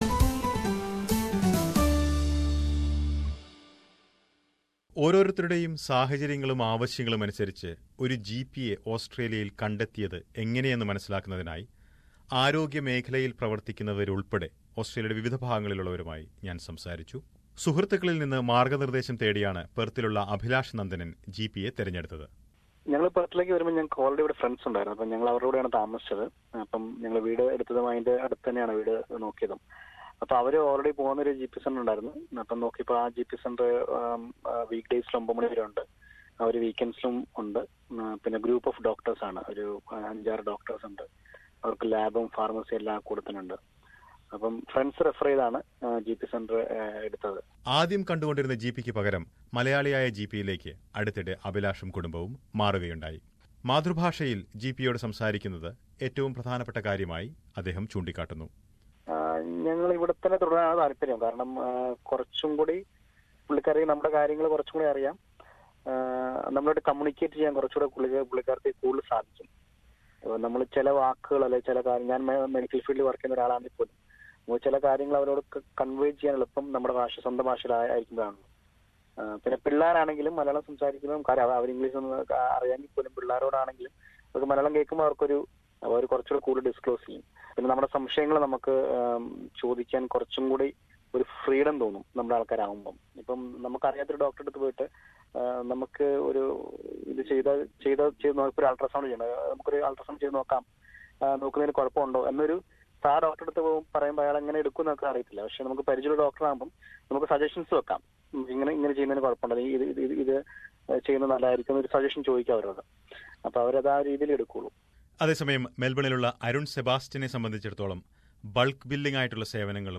Newly arrived migrants sometimes face some challenges in understanding the relevance of finding a family GP in their early days in Australia. Some health practitioners and migrants who have settled in Australia share their thoughts.